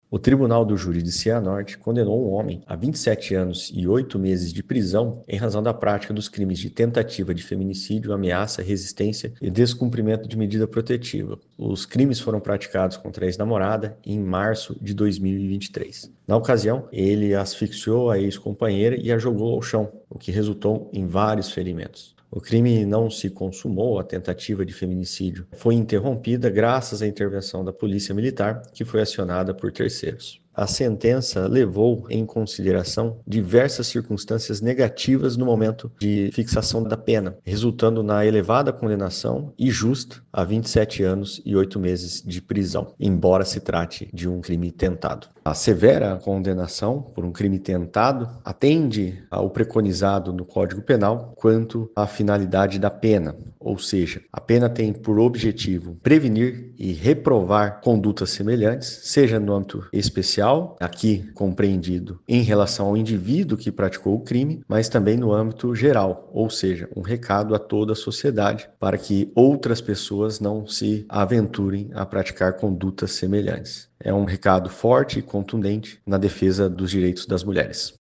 Ouça o que diz o promotor de Justiça, Guilherme Franchi da Silva Santos: